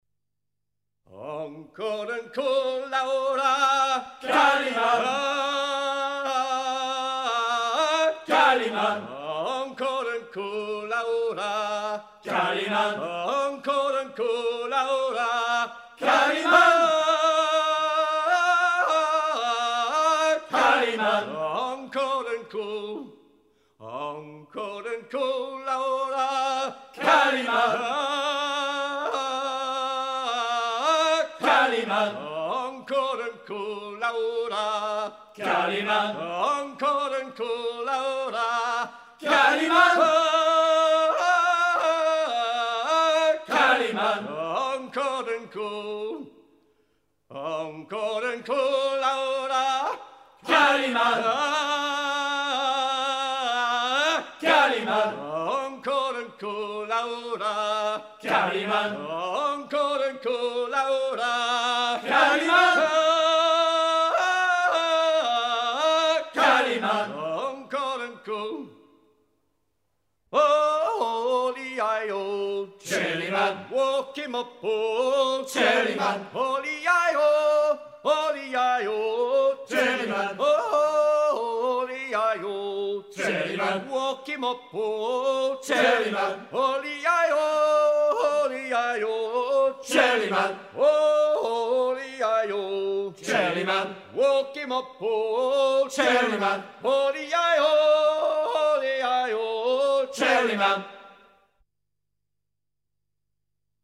chants brefs
à déhaler
Pièce musicale éditée